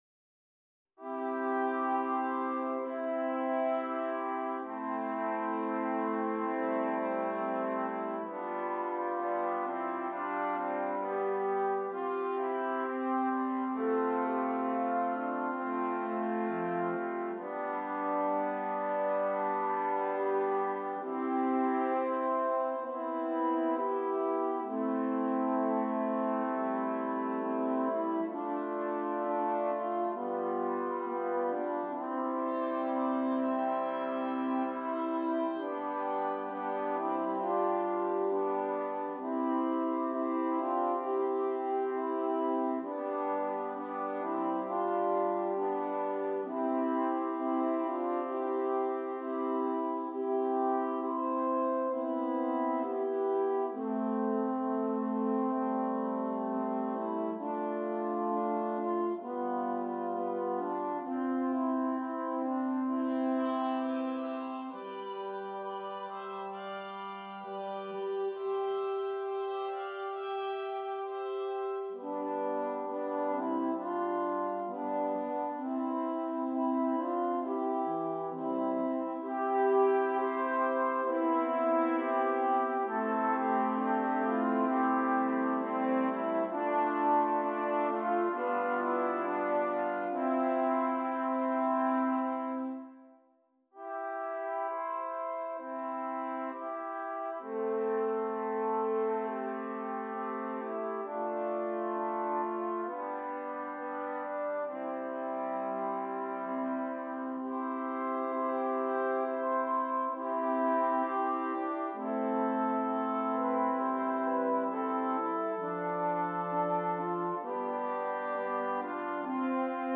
6 Trumpets
Traditional Austrian Carol